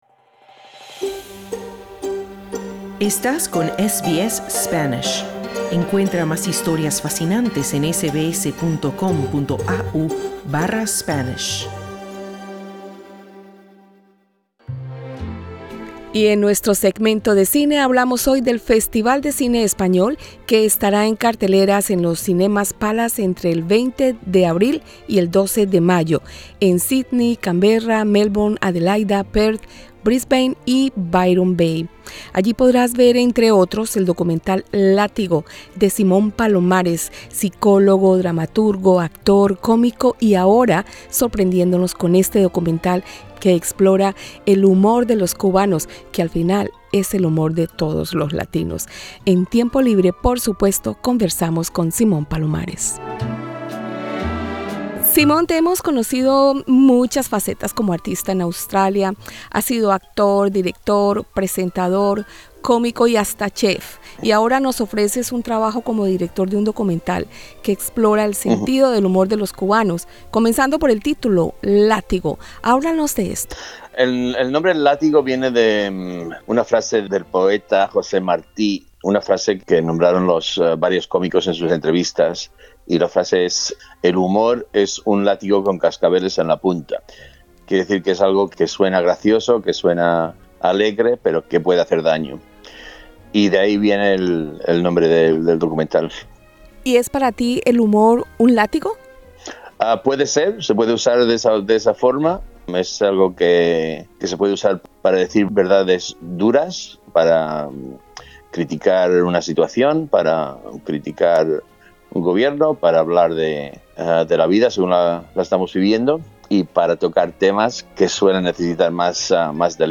En conversación con SBS Spanish